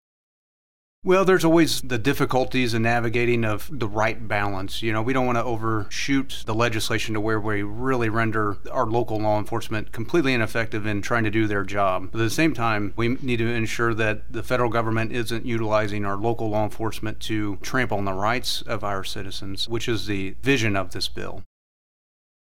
Audio: Sen. Rick Brattin Discusses SB 39